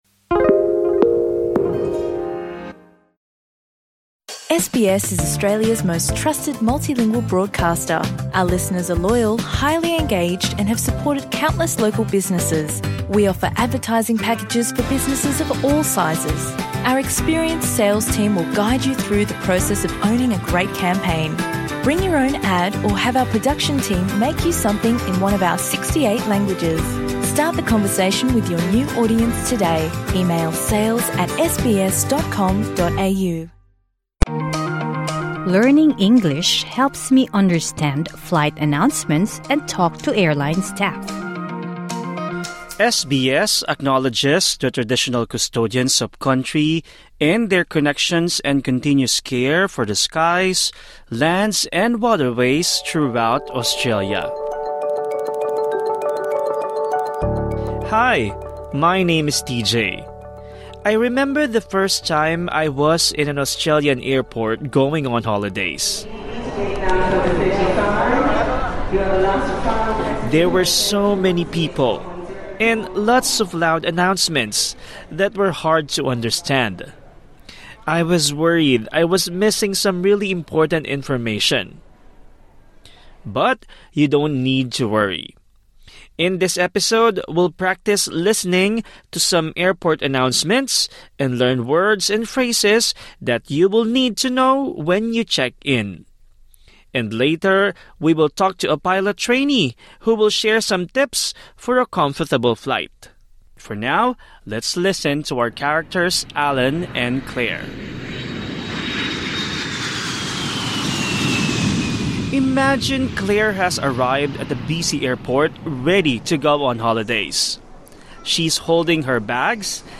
Practise listening to some airport announcements and learn words and phrases you need to know when you check in.